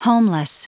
Prononciation du mot homeless en anglais (fichier audio)
Prononciation du mot : homeless